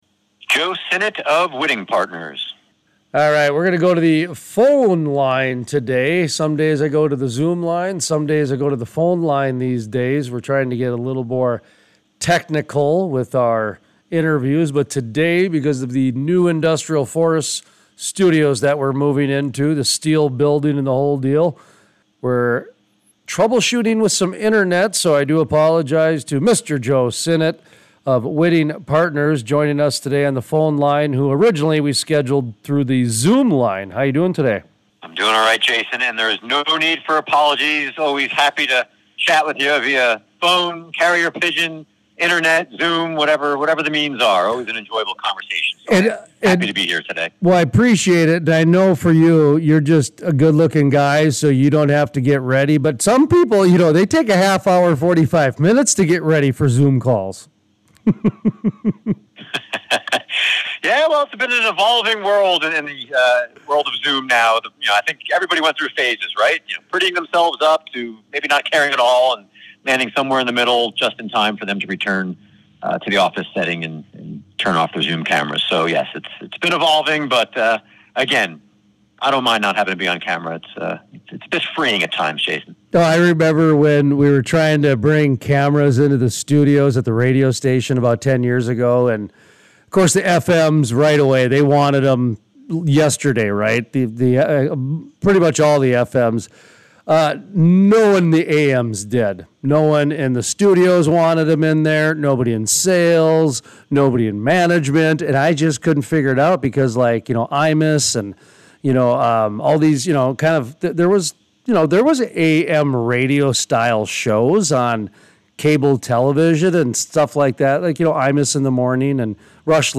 Full Length Interviews